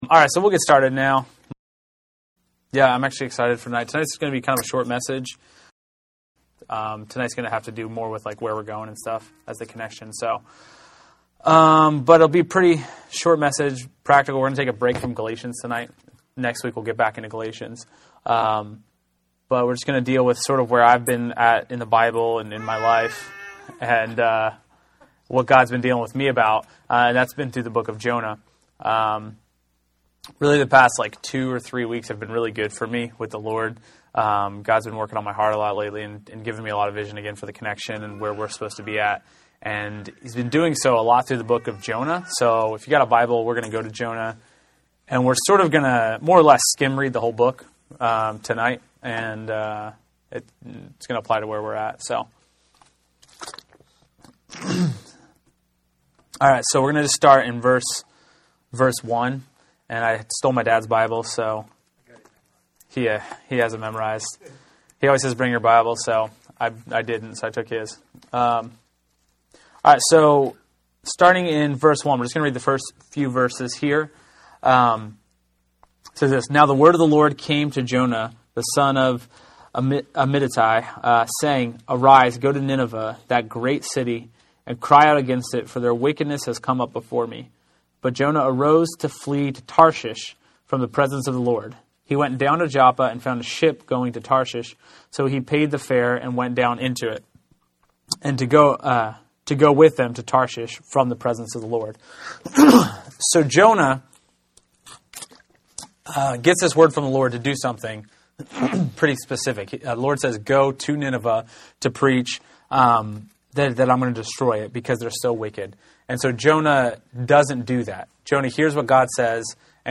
Latest Episode Jonah and the future of The Connection Download the latest episode Note: in some browsers you may have to wait for the whole file to download before autoplay will launch. Stand Alone Sermons This is where we archive sermons that stand alone and are not a part of any particular series.